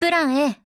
贡献 ） 协议：Copyright，其他分类： 分类:少女前线:SP9 、 分类:语音 您不可以覆盖此文件。